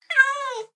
cat_meow1.ogg